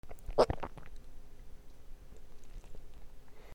のどの音 飲み込み